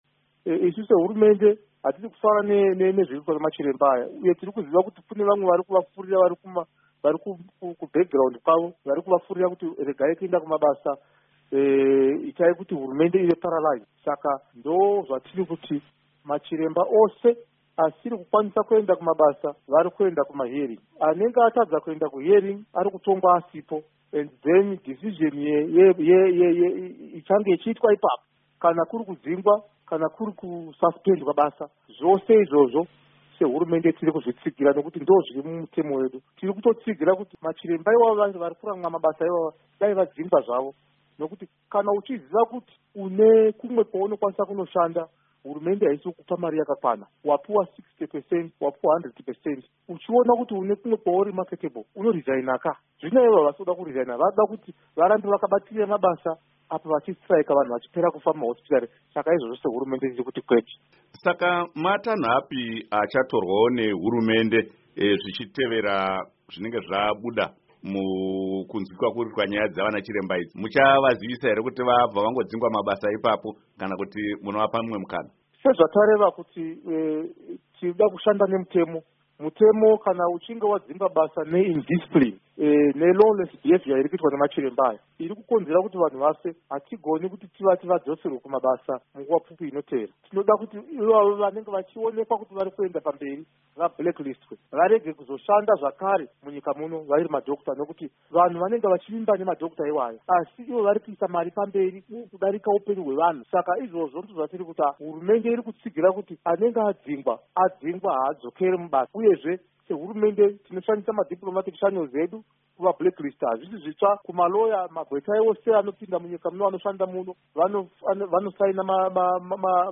Hurukuro naVaEnergy Mutodi